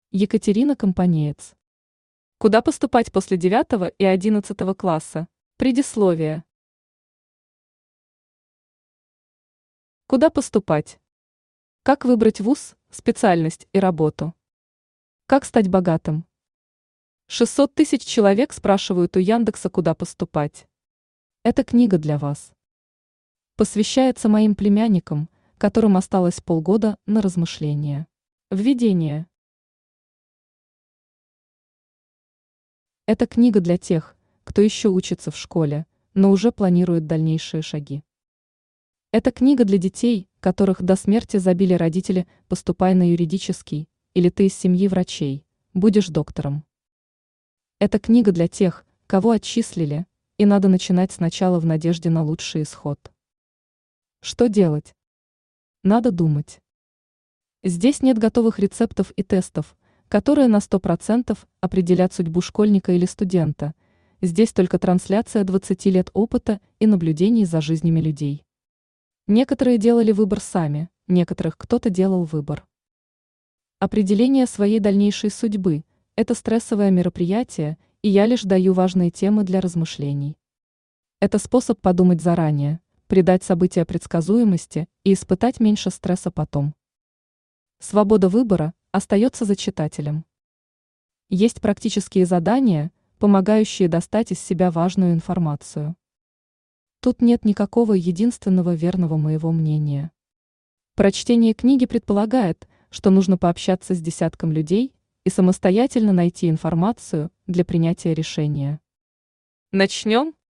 Аудиокнига Куда поступать после 9го и 11го класса?
Автор Екатерина Евгеньевна Компанеец Читает аудиокнигу Авточтец ЛитРес.